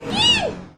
fail_panda.mp3